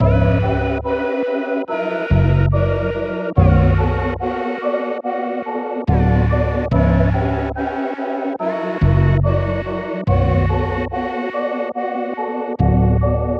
MELODY LOOPS
Visions (143 BPM – Bm)
UNISON_MELODYLOOP_Visions-143-BPM-Bm.mp3